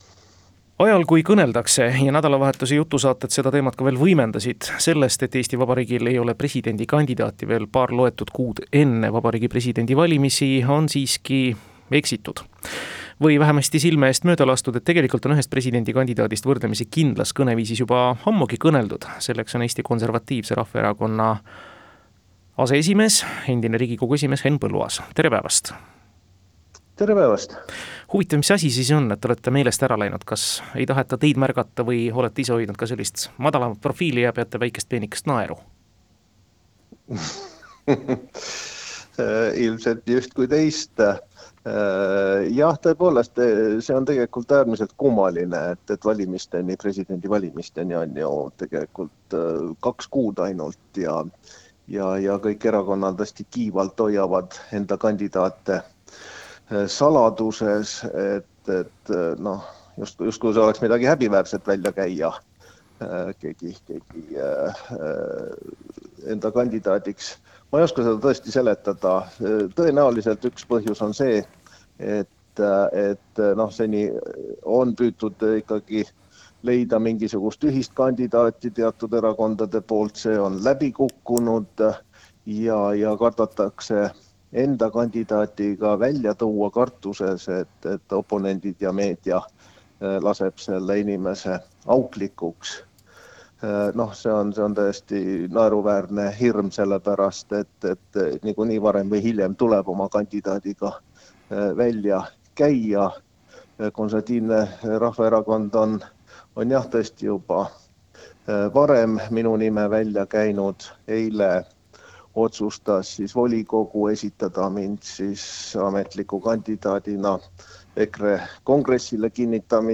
RAADIOINTERVJUU: Henn Põlluaas: eelmiste presidendivalimistega sarnast olukorda ei sooviks ükski erakond - Uued Uudised